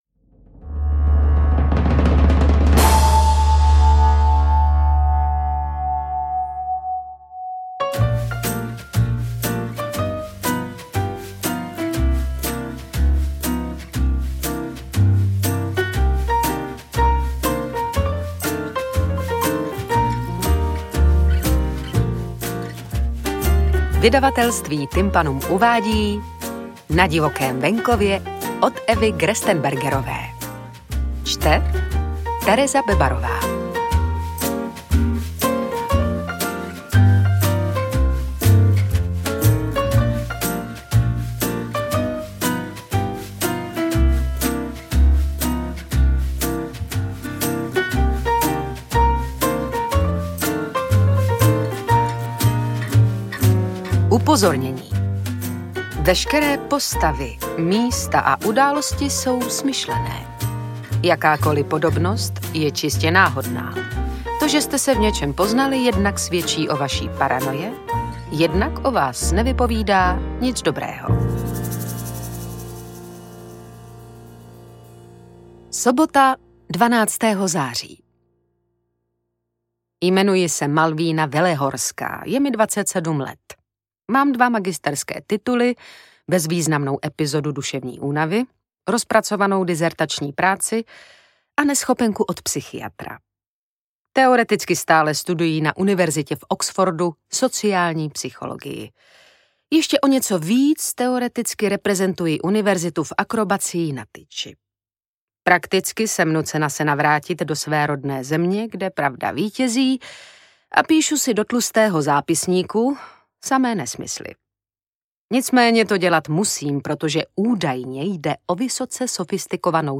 Interpret:  Tereza Bebarová
AudioKniha ke stažení, 17 x mp3, délka 5 hod. 5 min., velikost 279,4 MB, česky